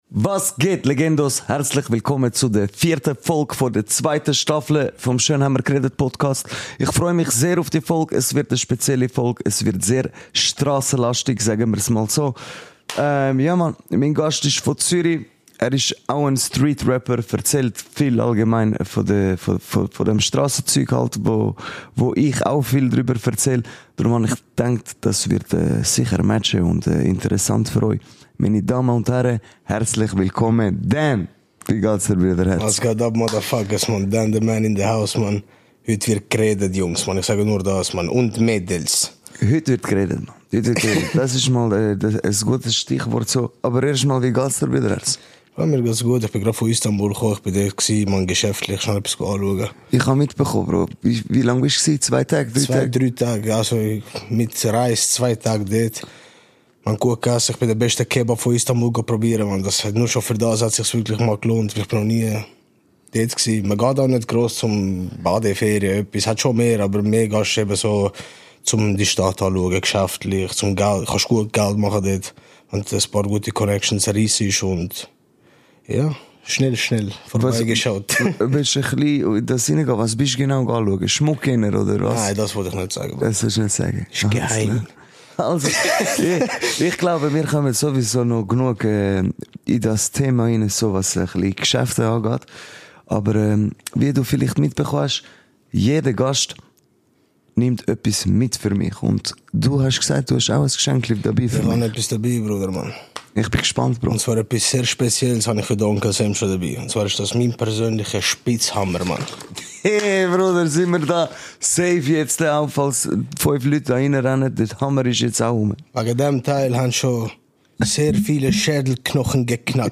Wir möchten darauf hinweisen, dass in dieser Podcast-Folge erwachsene Themen, Alkohol- und Drogenkonsum und offene Sprache behandelt werden.